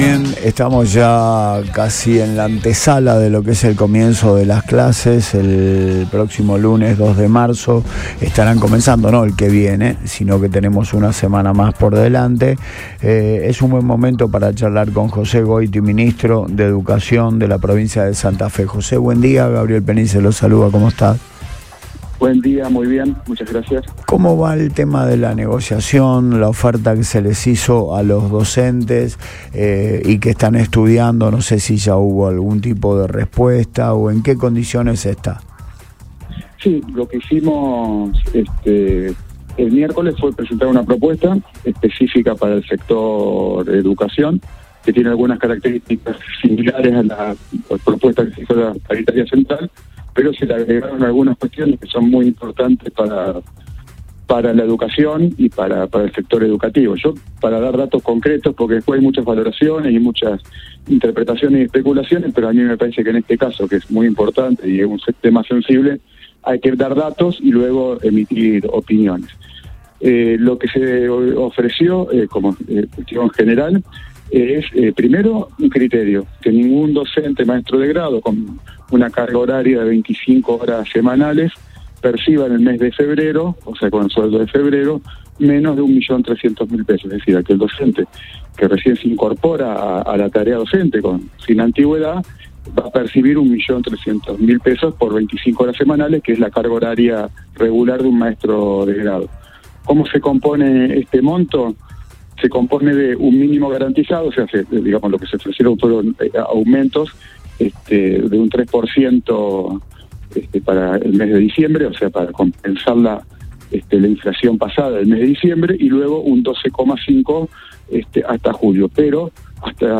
El ministro de Educación, José Goity, pasó por los micrófonos de "Antes de Todo" en Radio Boing para dar detalles de la propuesta salarial presentada a los gremios.
Jose Goity, ministro de Educación de Santa Fe.